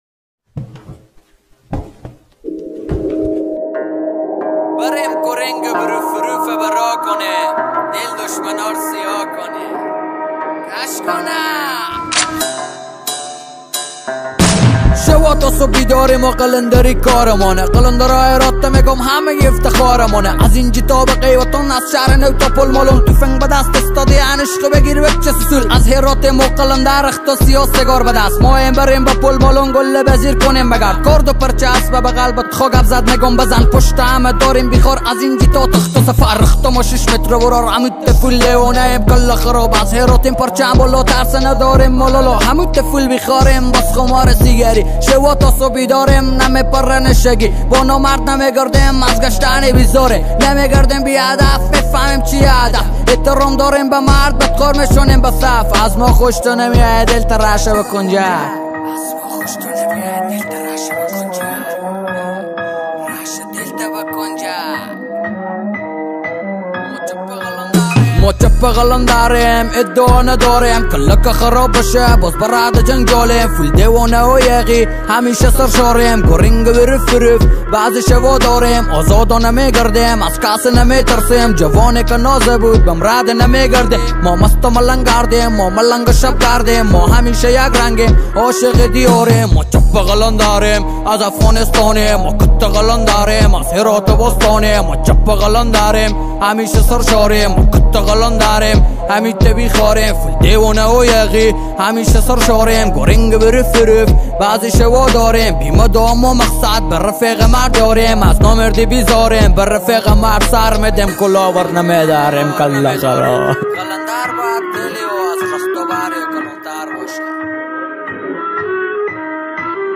رپ افغانی